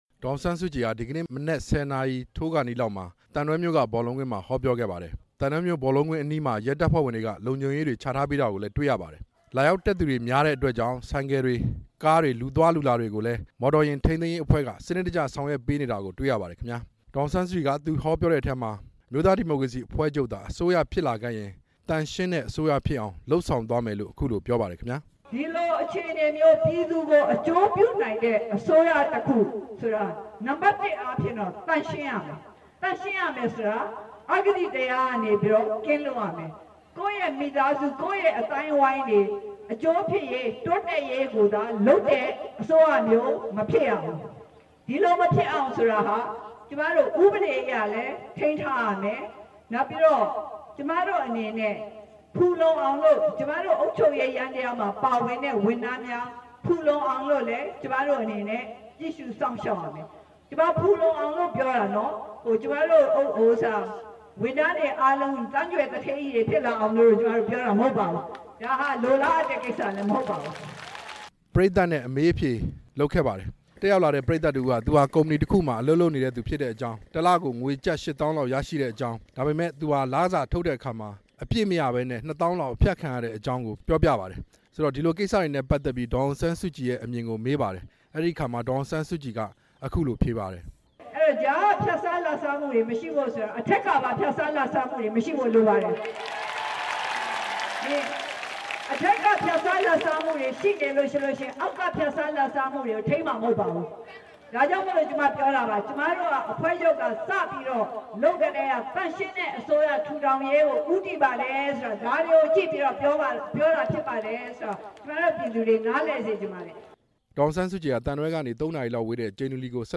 dassk-thandwe-620.jpg ရခိုင်ပြည်နယ် သံတွဲမြို့က ရွေးကောက်ပွဲအောင်နိုင်ရေး ဟောပြောပွဲမှာ အောက်တိုဘာလ ၁၇ ရက်နေ့က အမျိုးသားဒီမိုကရေစီ အဖွဲ့ချုပ် ဥက္ကဌ ဒေါ်အောင်ဆန်းစုကြည် ဟောပြောစဉ်
ဒီကနေ့ ရခိုင်ပြည်နယ် သံတွဲမြို့က ရွေးကောက်ပွဲအောင်နိုင်ရေး ဟောပြောပွဲမှာ တက်ရောက်လာတဲ့ ပရိတ်သတ် တစ်ဦးက ဒေါ်အောင်ဆန်းစုကြည်ဟာ RSO ရိုဟင်ဂျာအဖွဲ့အစည်းကို စာရေးအကူအညီတောင်းတယ်ဆိုပြီး အင်တာနက် လူမှုရေးစာမျက်နှာတွေမှာပျံ့နေတာ ဟုတ်ပါသလားလို့ မေးမြန်းရာမှာ ဒေါ်အောင်ဆန်းစုကြည် က ဖြေကြားလိုက်တာ ဖြစ်ပါတယ်။